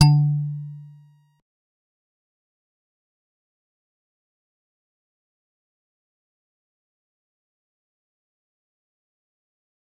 G_Musicbox-D3-pp.wav